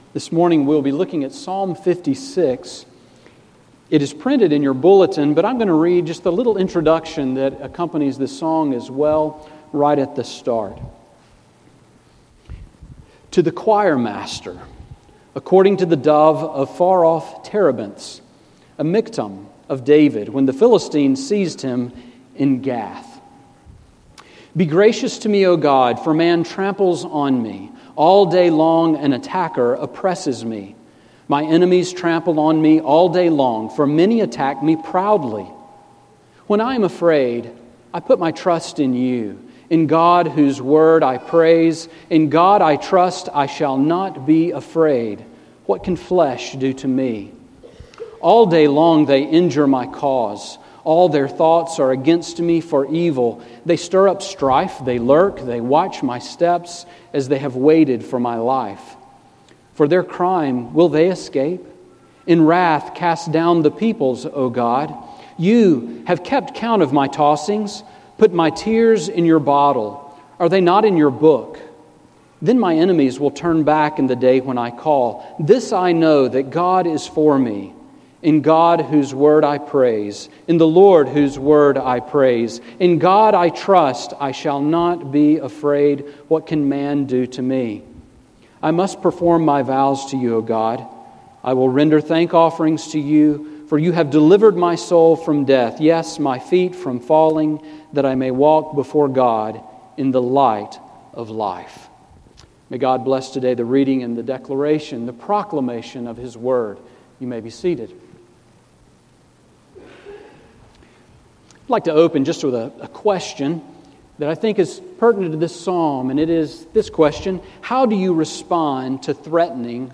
Sermon on Psalm 56 from July 26